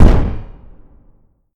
fl_expl.ogg